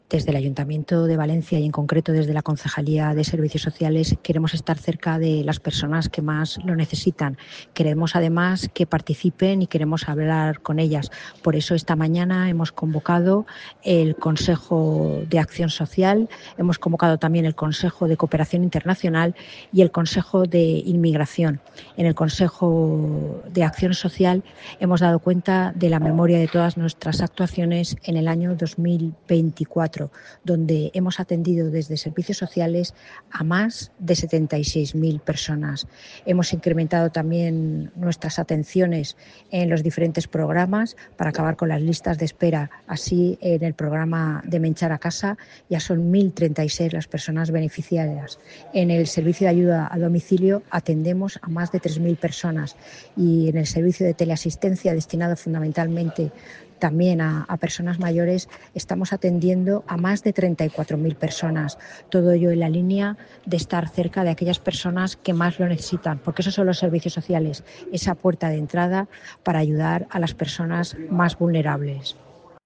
Corte de voz de la concejal de Bienestar Social, Marta Torrado.